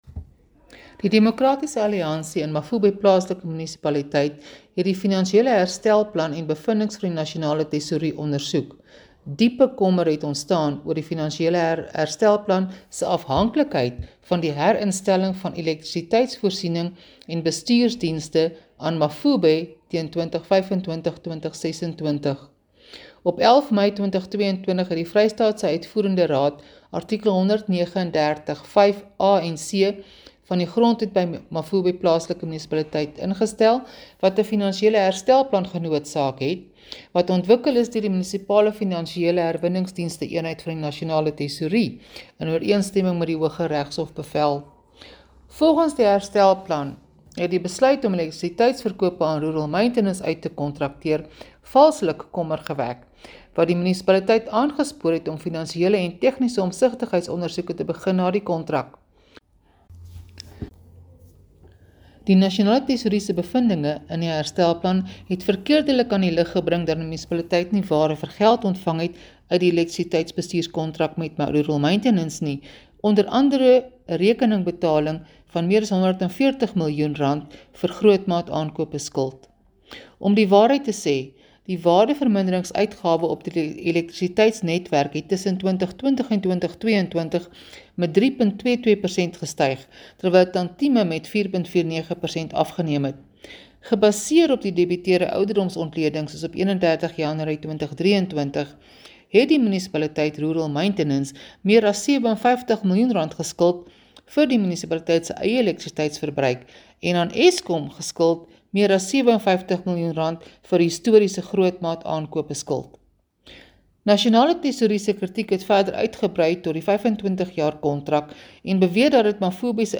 Afrikaans soundbites by Cllr Suzette Steyn and Sesotho by Karabo Khakhau MP.